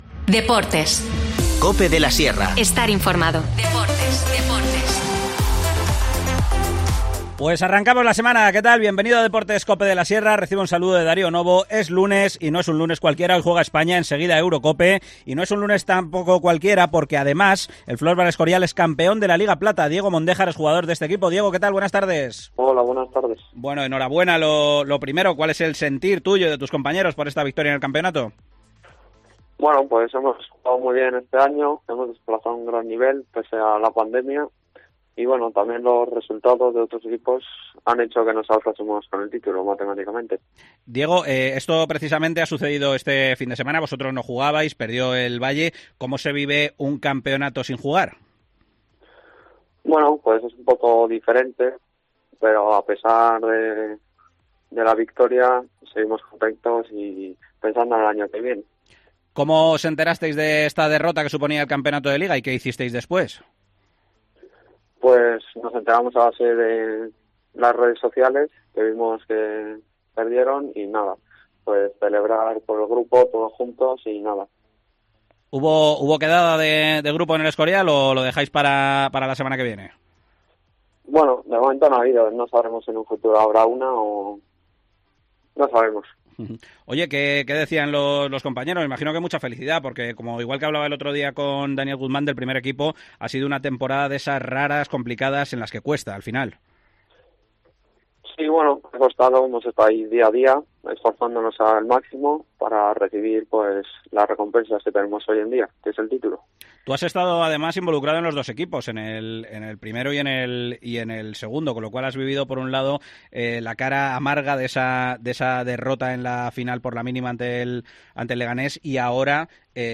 Octavos de final para España que se enfrenta a Croacia. Hemos sacado los micrófonos a la calle para palpar el optimismo de los vecinos con la selección de Luis Enrique.